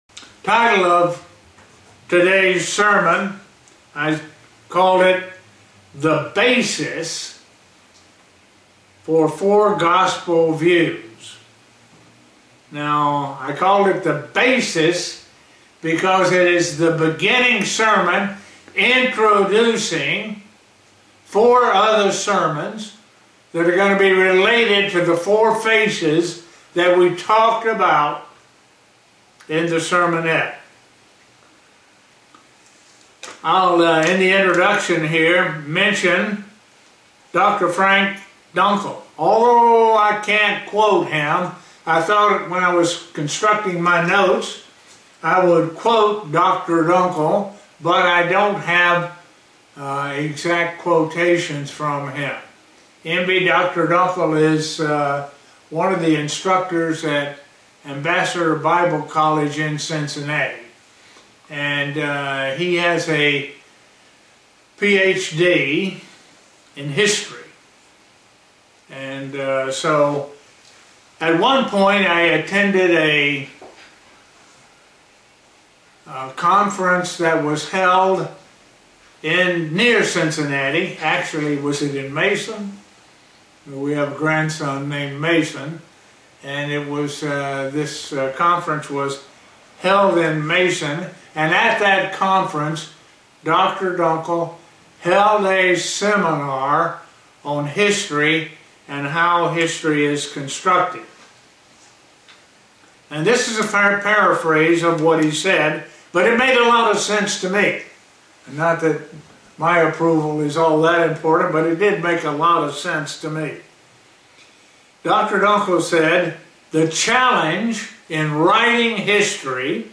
Given in Buffalo, NY
Print The individual writer of the Gospels show Christ in a different way by what they add or leave out. sermon Studying the bible?